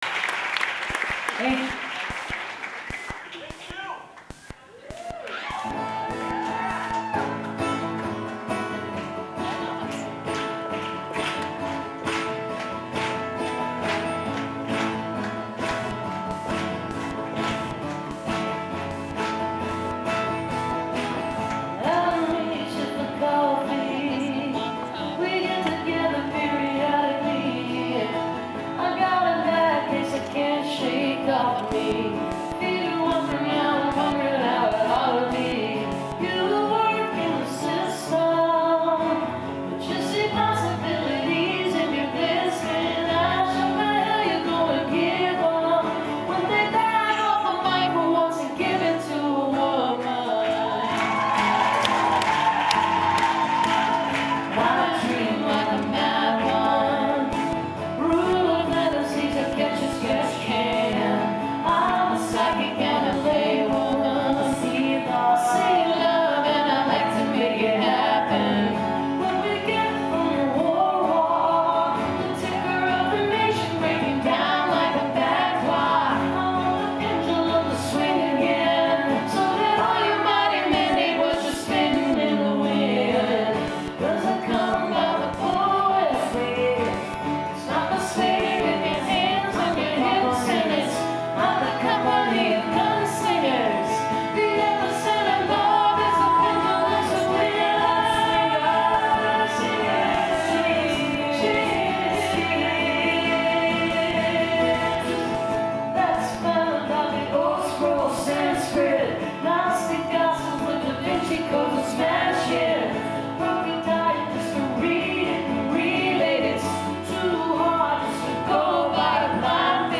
zankel hall - carnegie (acjw) - new york, new york